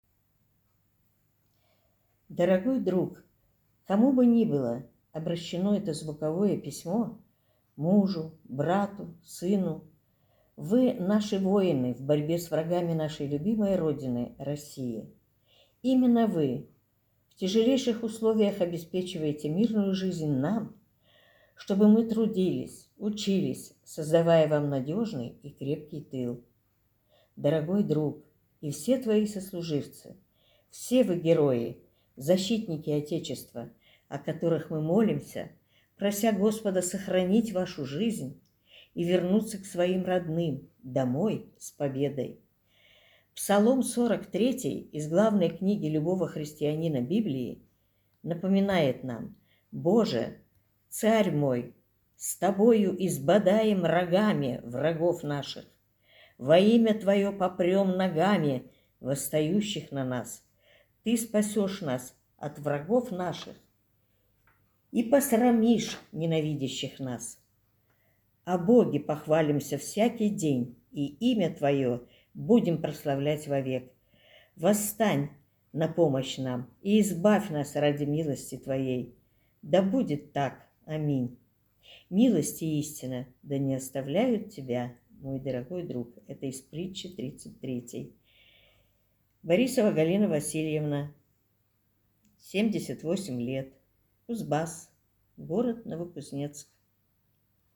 Незрячие и слабовидящие читатели пишут «звуковые» письма в поддержку героев СВО.